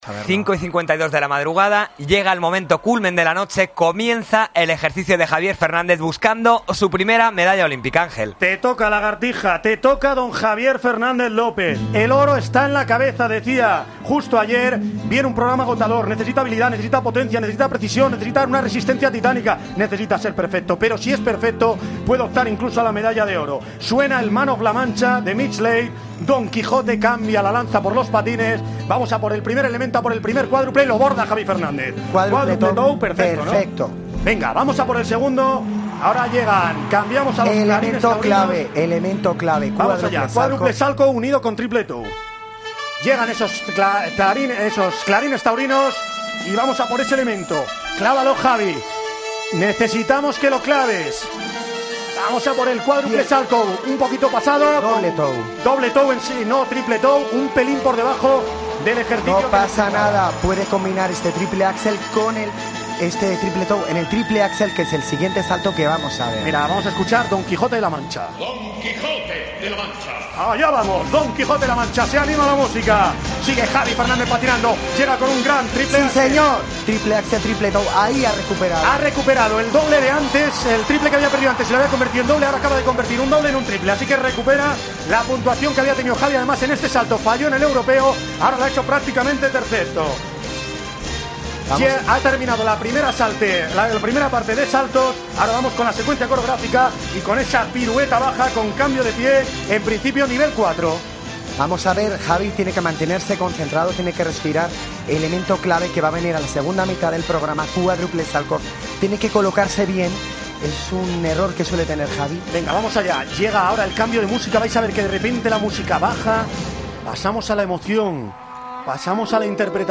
Así narramos en COPE el ejercicio que dio a Javier Fernández la medalla de bronce olímpica